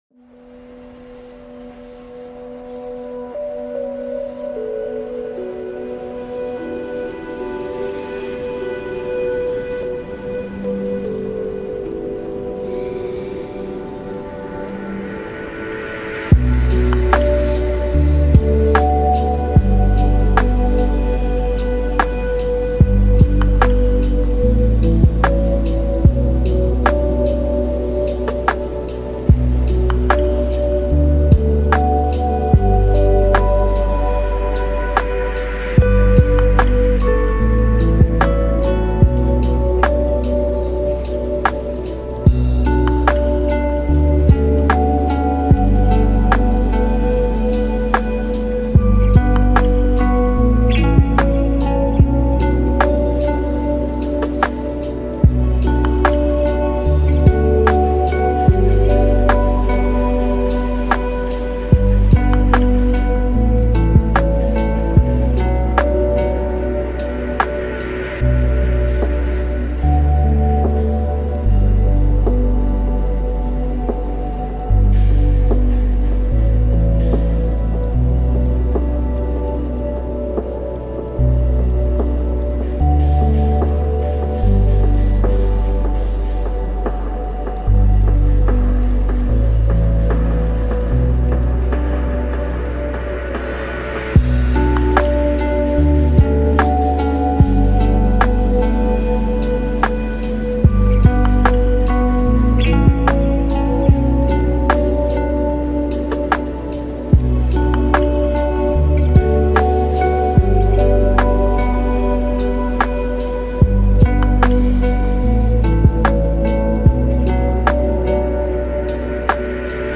your ultimate destination for calming vibes, chill beats
lo-fi music